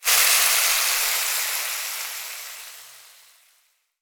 cooking_sizzle_burn_fry_05.wav